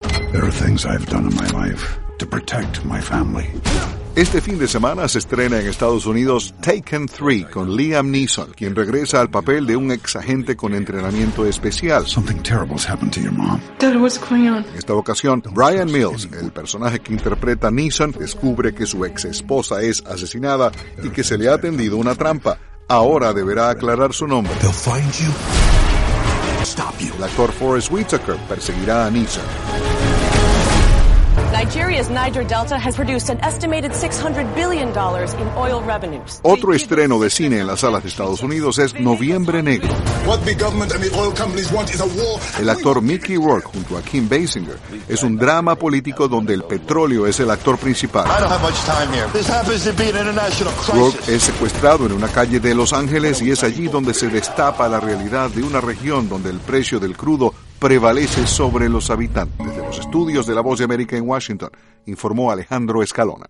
Noticias del mundo del Espectáculo